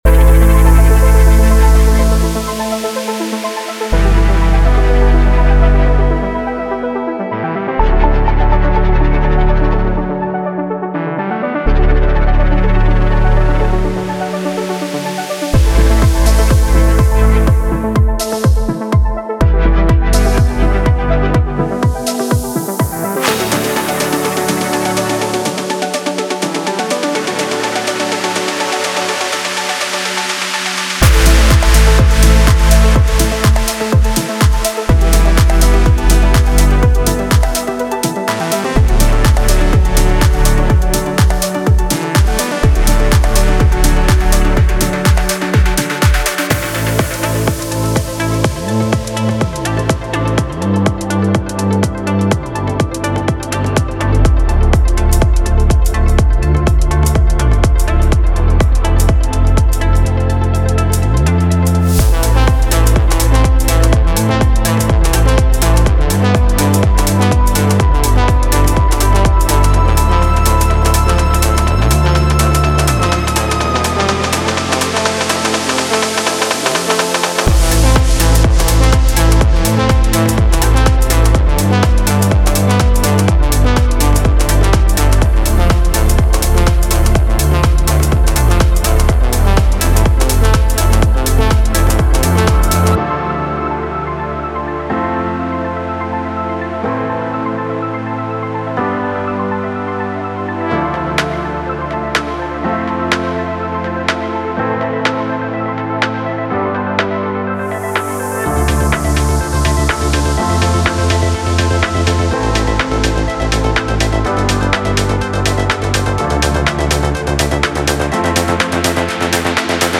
Genre:Progressive House
デモサウンドはコチラ↓